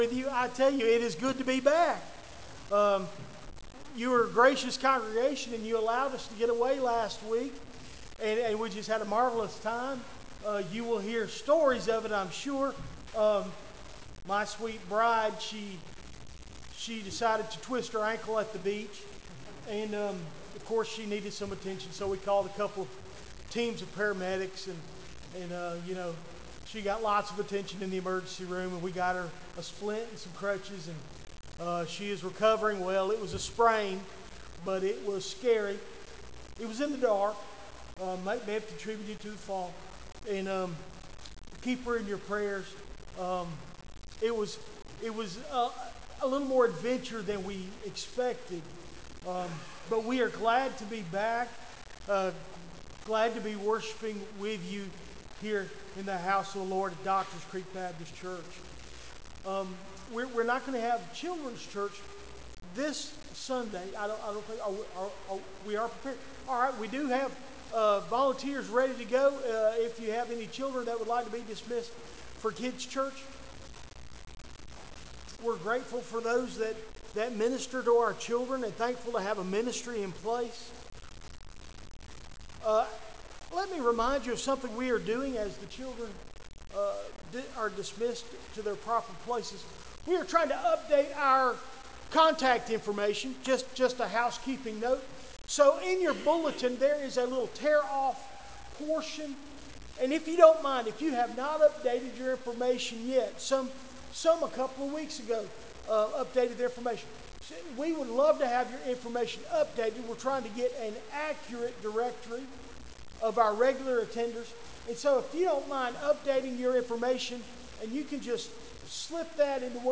Recent Sermons - Doctor's Creek Baptist Church